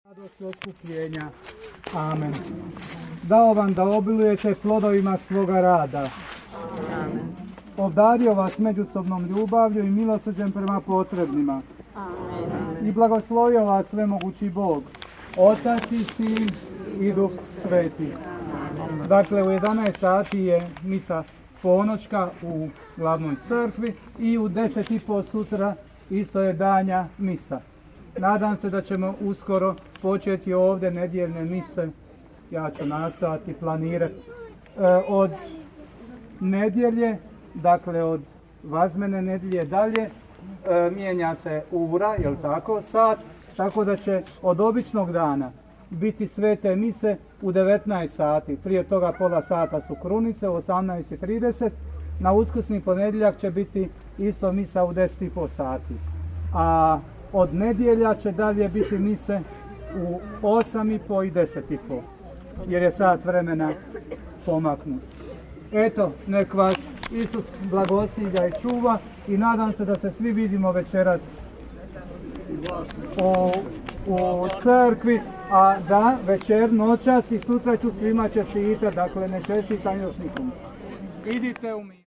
OBAVIJESTI I BLAGOSLOV
BLAGOSLOV HRANE U OKRUKU DONJEM